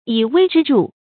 以微知著 yǐ wēi zhī zhù
以微知著发音
成语正音微，不能读作“huī”。